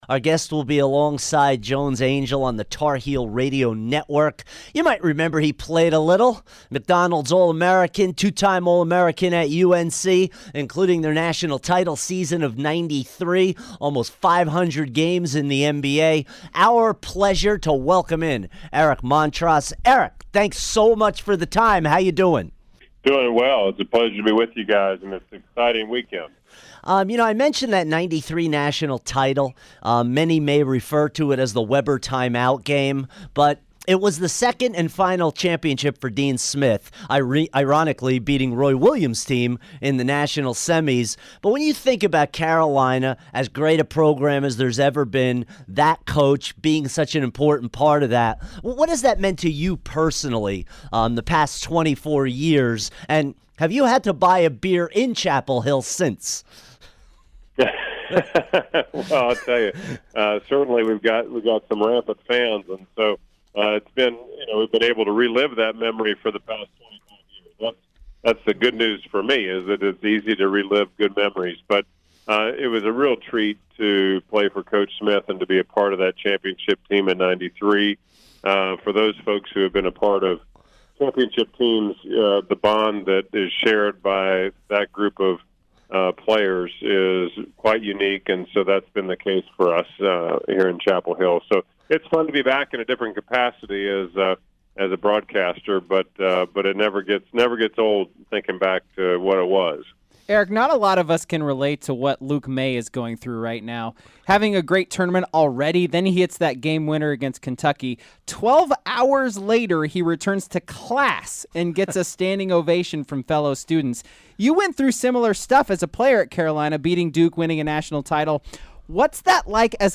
Eric Montross Interview 3-29-17